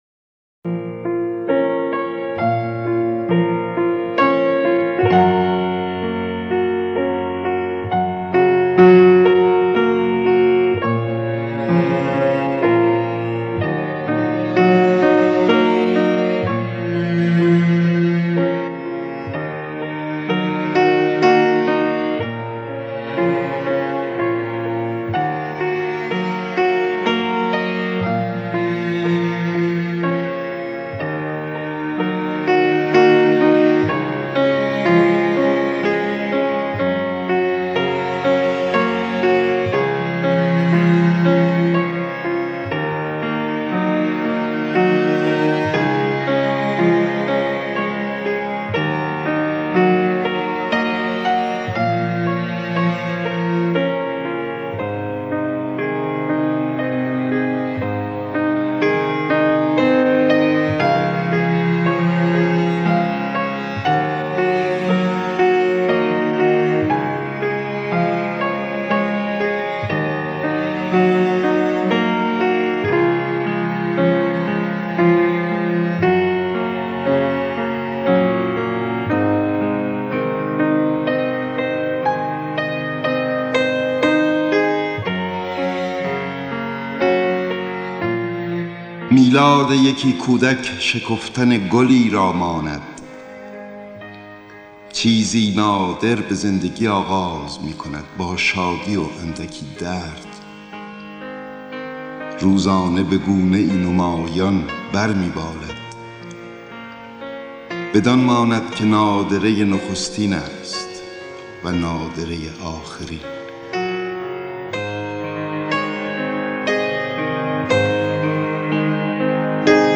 دانلود دکلمه میلاد یکی کودک با صدای احمد شاملو
گوینده :   [احمد شاملو]
آهنگساز :   بابک بیات